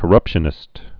(kə-rŭpshə-nĭst)